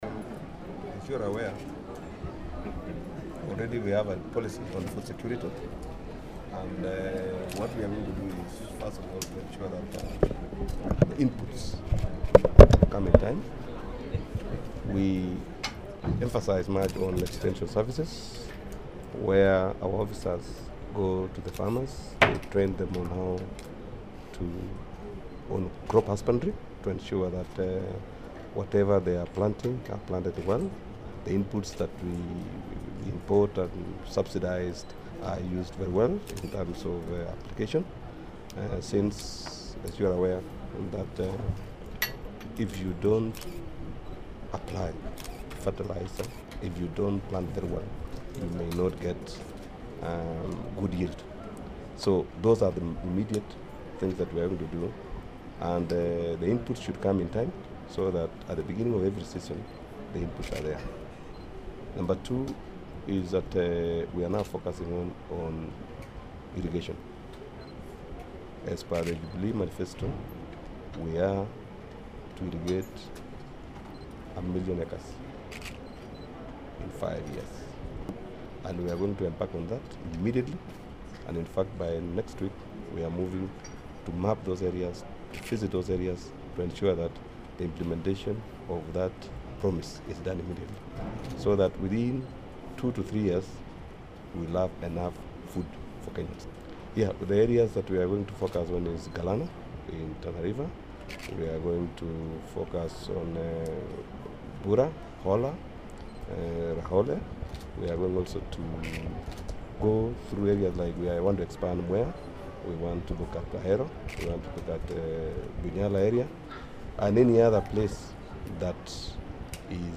Intvw w/ Kenya's Secretary for Agriculture, Livestock and Fisheries, Felix Kiptarus Koskei